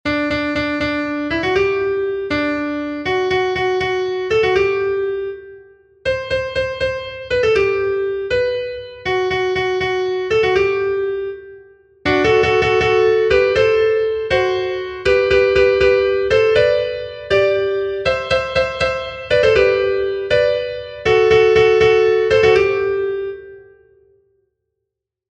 Sentimenduzkoa
Zortziko ertaina (hg) / Lau puntuko ertaina (ip)
A-B-C-B